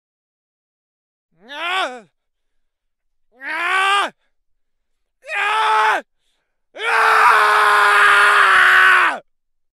دانلود صدای داد و فریاد مرد 1 از ساعد نیوز با لینک مستقیم و کیفیت بالا
جلوه های صوتی